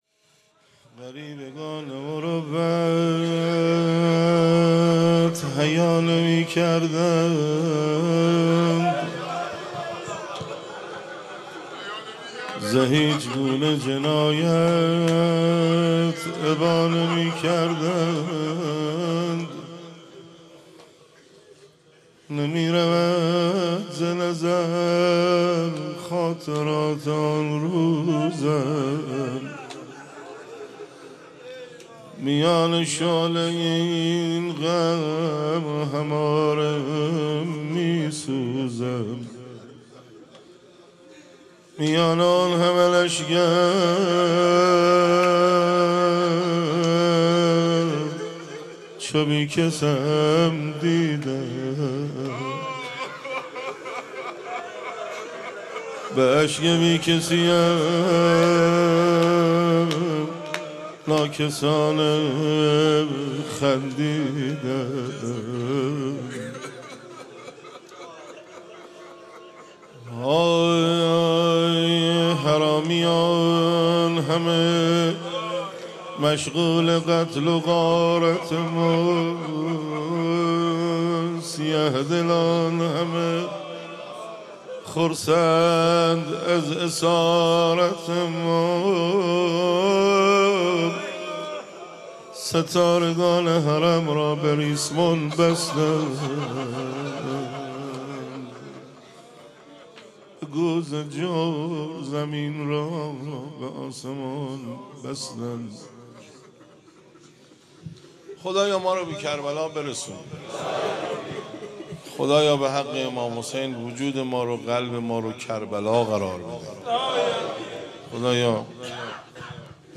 مناسبت : دهه دوم صفر
قالب : روضه